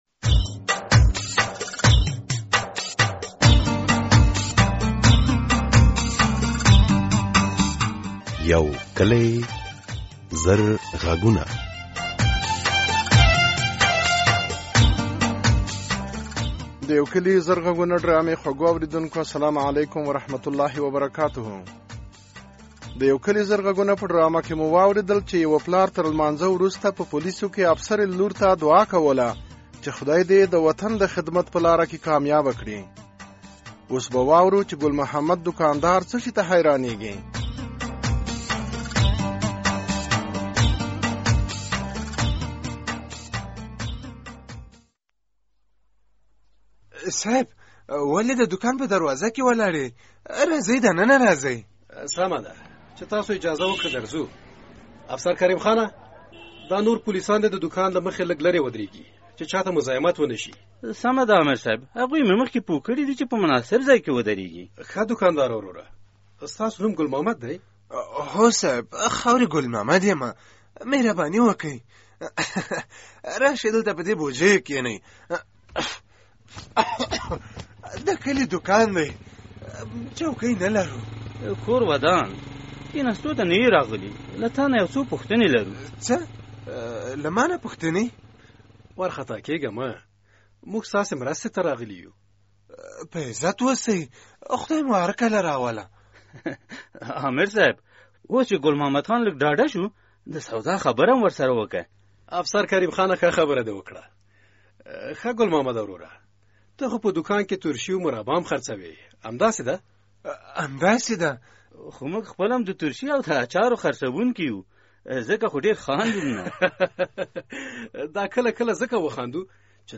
د یو کلي زرغږونو ډرامې په ۲۰۹ برخه کې د ګڼو نورو موضوعاتو تر څنګ د کلي د انجونو له لېوالتیا خبرېږی چې ښوونځي ...